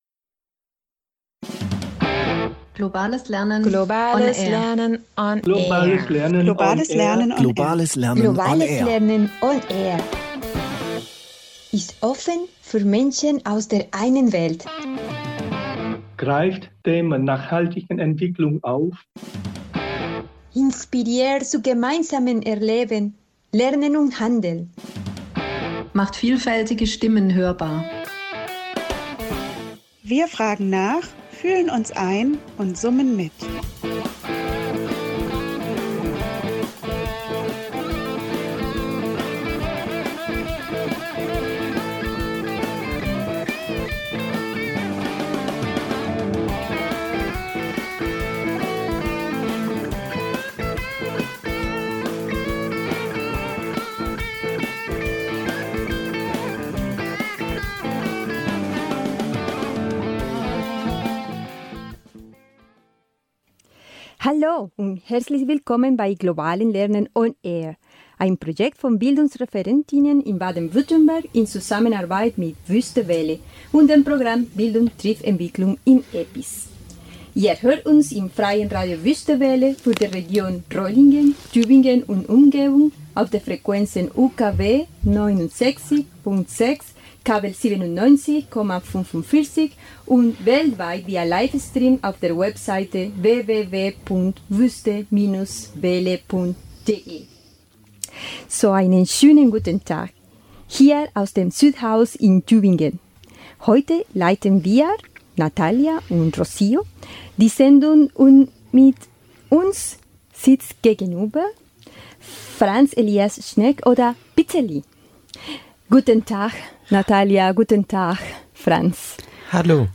Wir danken ihm herzlich für das Interview und seine Offenheit zu einem Thema, das oft wenig Beachtung findet.